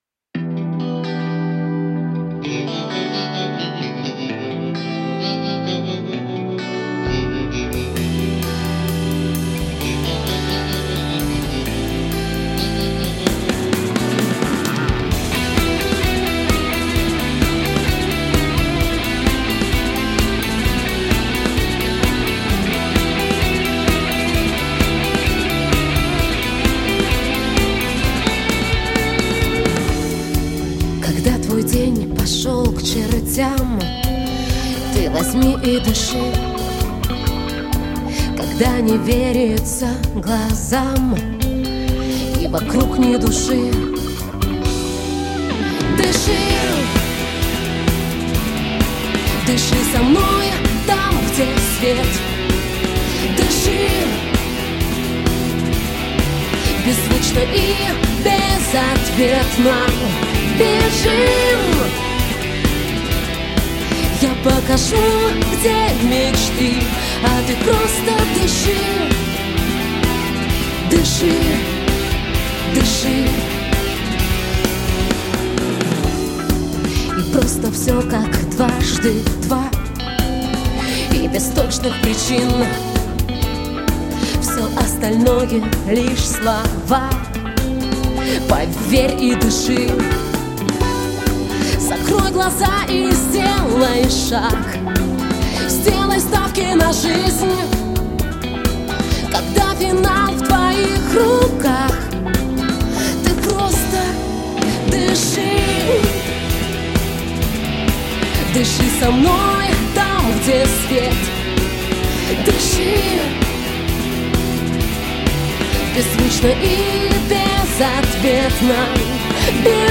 [Pop-rock]